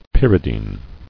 [pyr·i·dine]